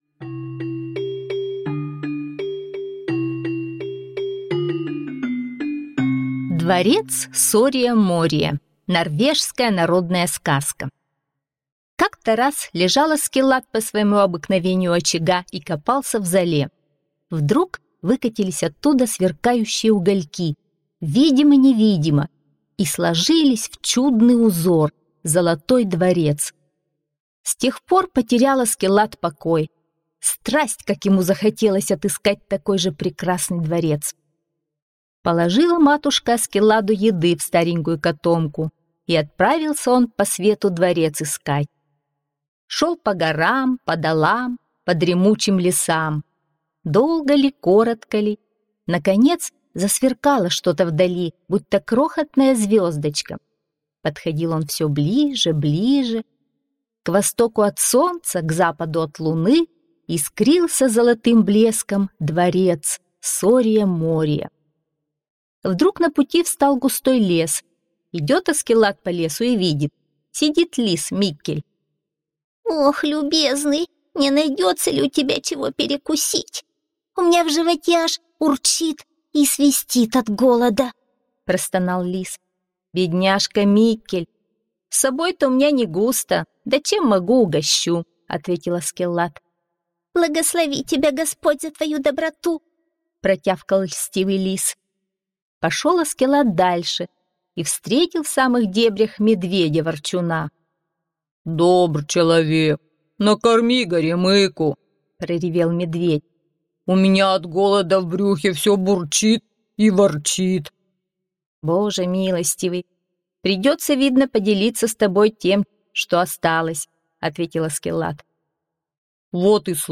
Дворец Сория-Мория - норвежская аудиосказка - слушать онлайн